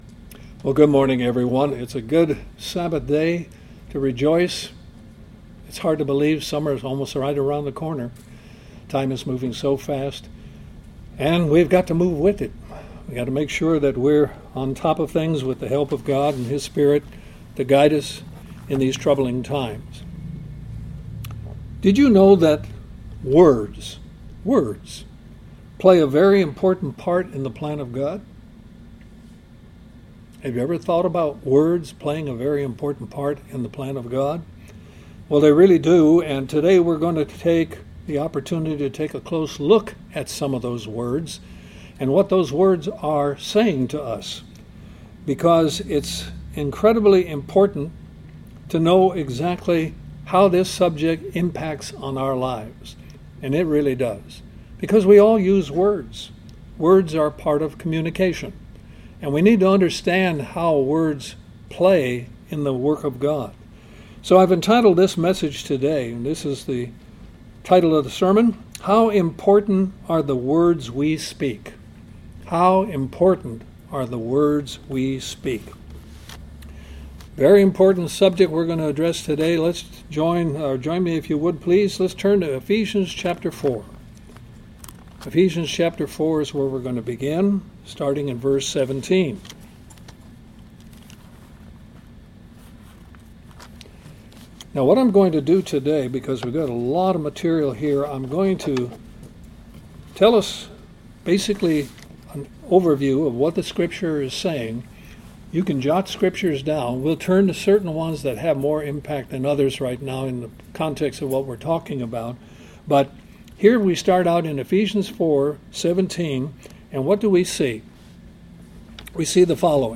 Sermons
Given in Columbus, GA Central Georgia